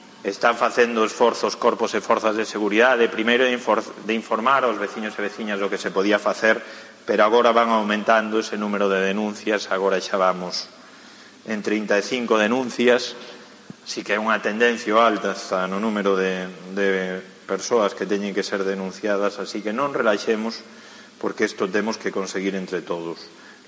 AUDIO: El alcalde de Vilagarcía, Alberto Varela, informa del aumento de las denuncias